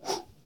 bows_shoot.ogg